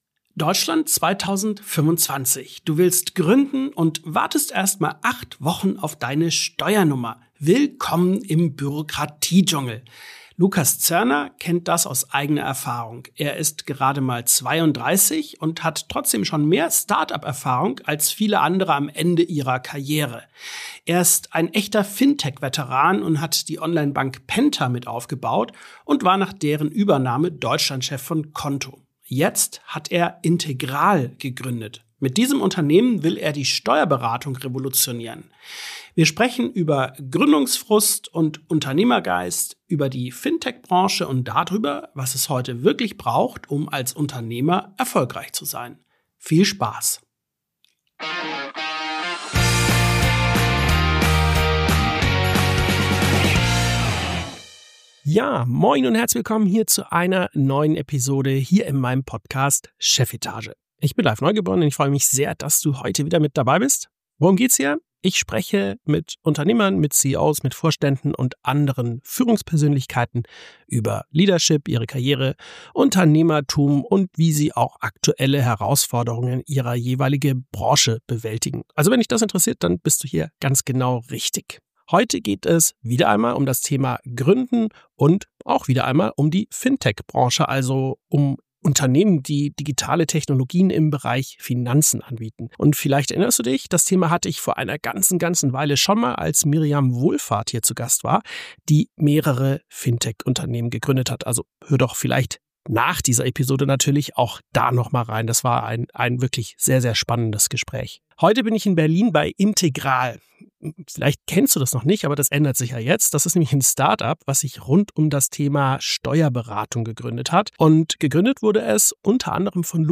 72 ~ Chefetage - CEOs, Unternehmer und Führungskräfte im Gespräch Podcast